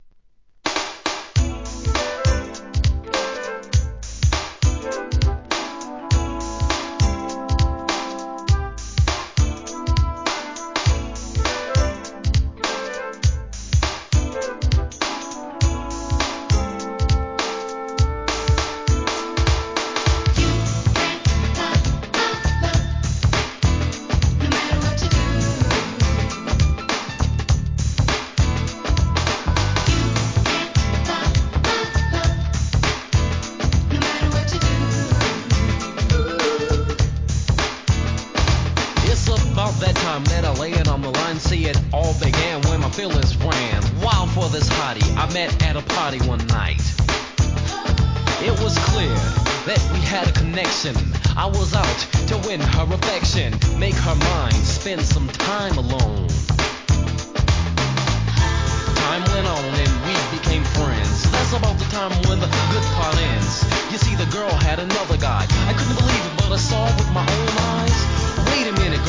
HIP HOP/R&B
JAZZYなNEW JACK SWINGで根強い人気曲!!!